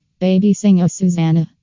Play Baby Dog - SoundBoardGuy
Play, download and share Baby dog original sound button!!!!